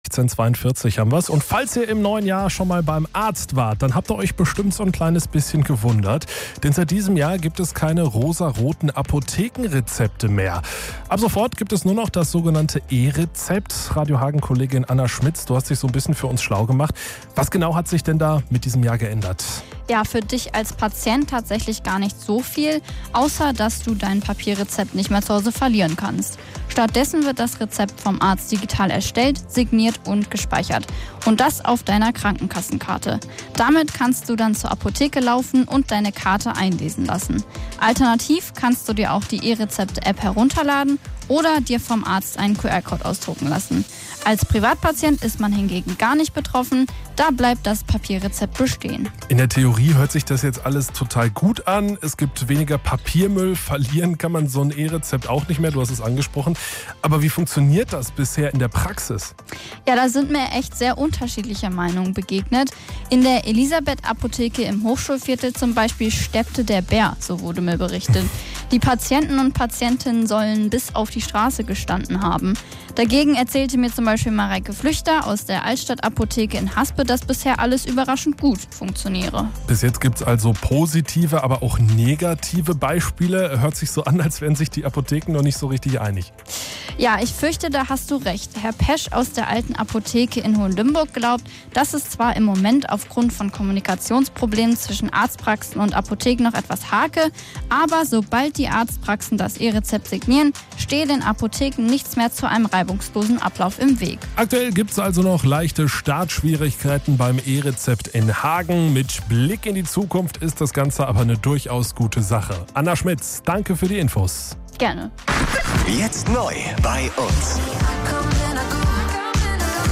Mitschnitt